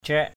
/crɛʔ/ (t.) sớm. early. padai craik p=d =cK lúa sớm.